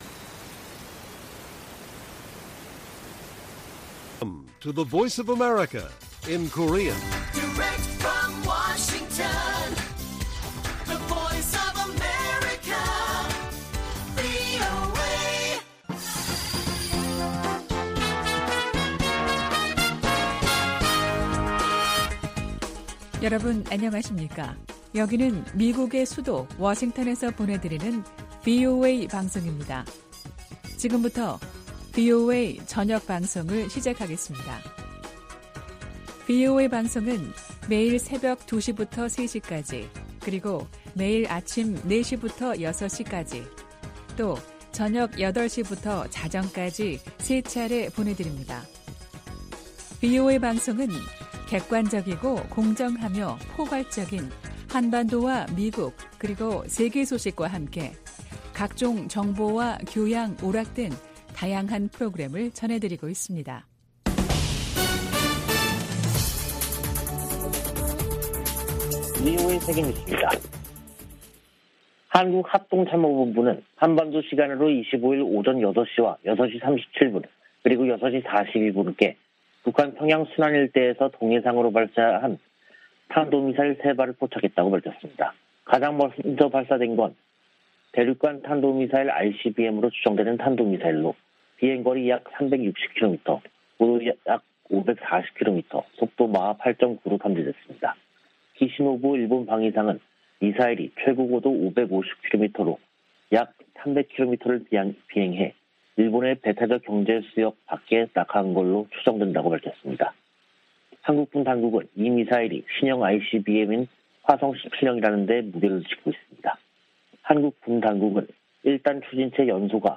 VOA 한국어 간판 뉴스 프로그램 '뉴스 투데이', 2022년 5월 25일 1부 방송입니다. 북한이 ICBM을 포함한 탄도미사일 3발을 동해상으로 발사했습니다. 미-한 군 당국은 미사일 실사격과 전투기 훈련 등으로 공동 대응했습니다. 미 국무부는 북한의 핵실험 등 추가 도발 가능성을 여전히 우려하고 있으며 적절한 책임을 물릴 것이라고 밝혔습니다.